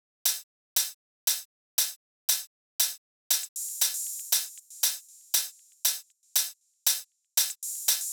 12 Hihat.wav